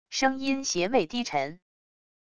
声音邪魅低沉wav音频